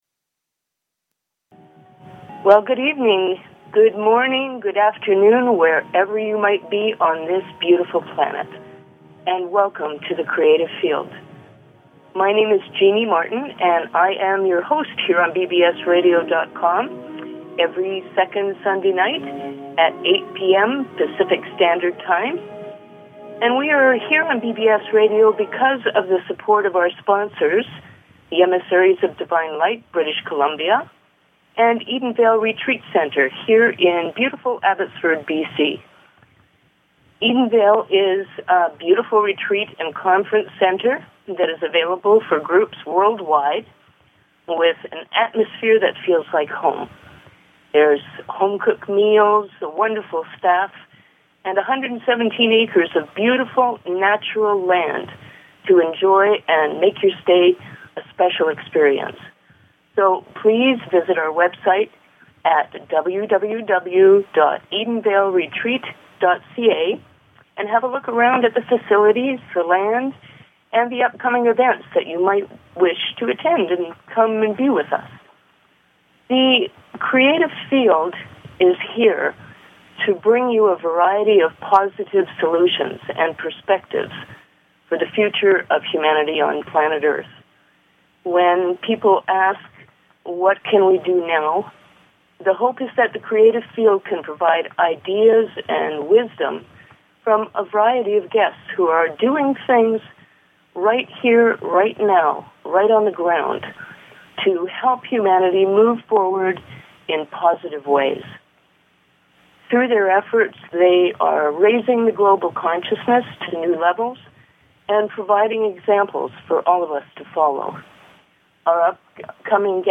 Talk Show Episode, Audio Podcast, The_Creative_Field and Courtesy of BBS Radio on , show guests , about , categorized as